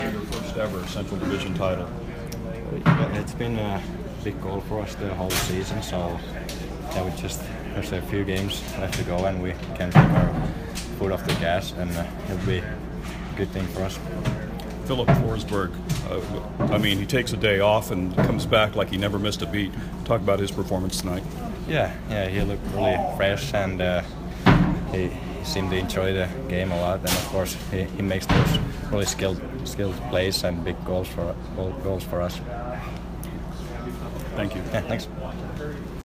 Juuse Saros post-game 4/1